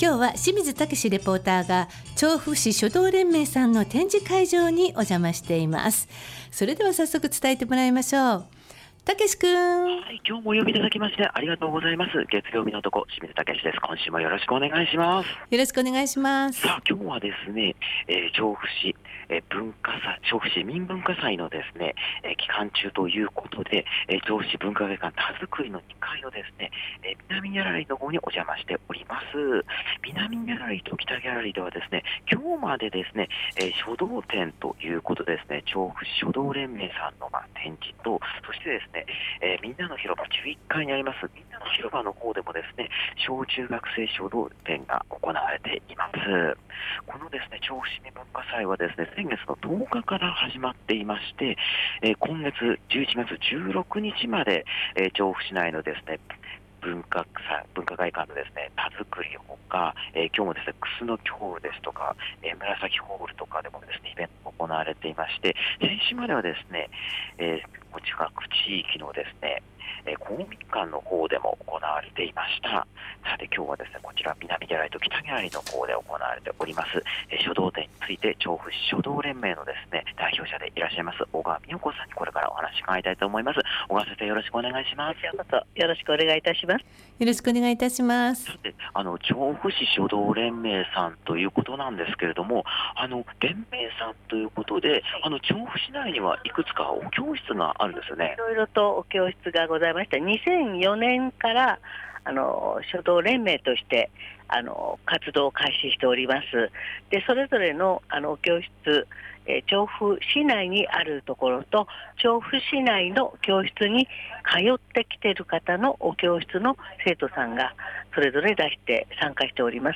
すっきりしない秋晴れの空の下からお届けした本日の街角レポートは、文化の日という事で文化会館たづくり２F南・北ギャラリーで開催中の 調布市書道連盟「書道展」からのレポートです！